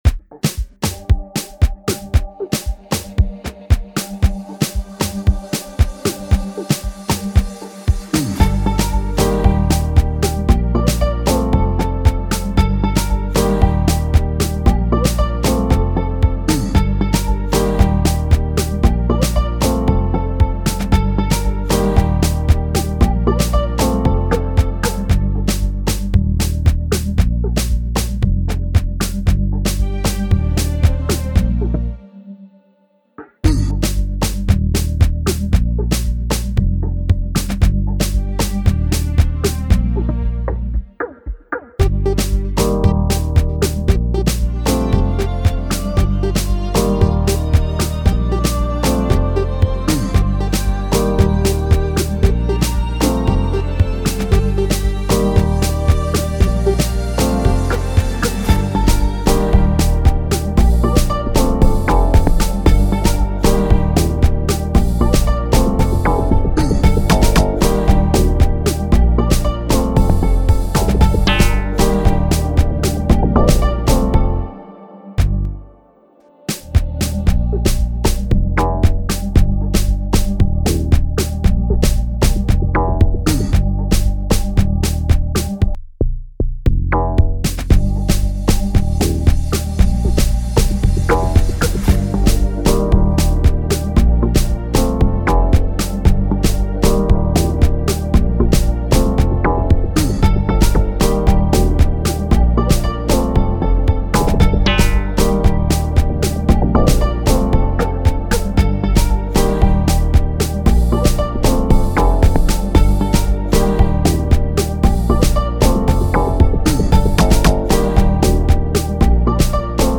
official instrumental
2025 in Dancehall/Afrobeats Instrumentals